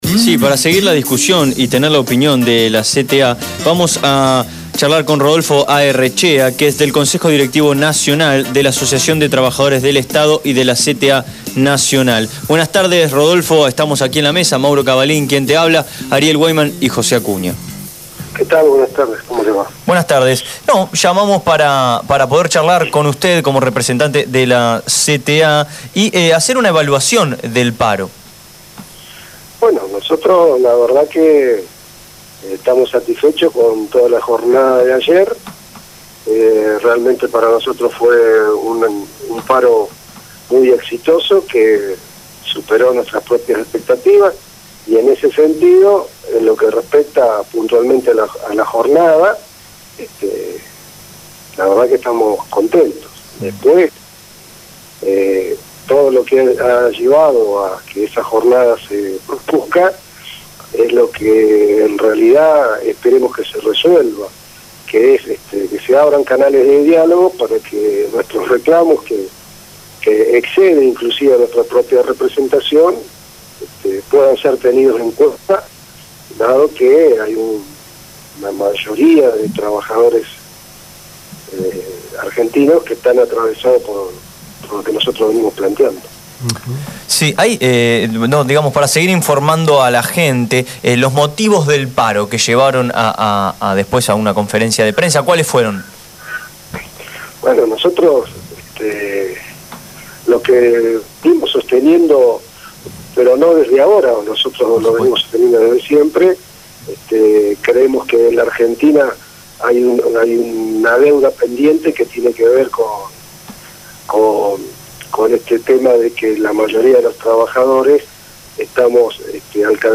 habló en Abramos la Boca.